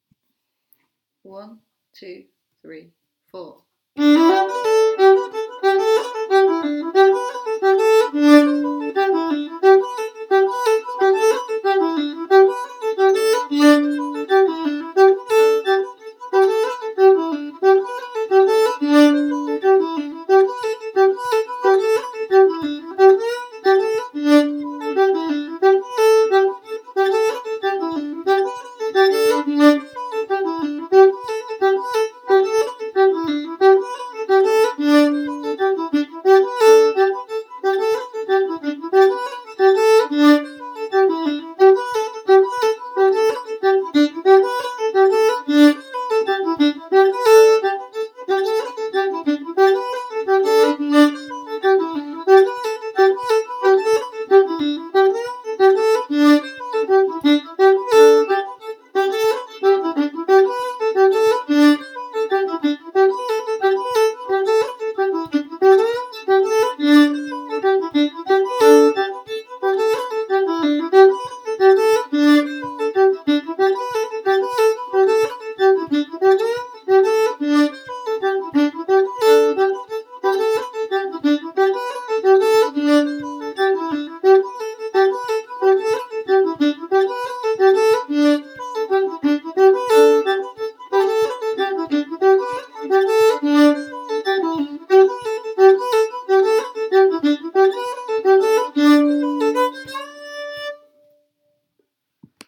A Part Only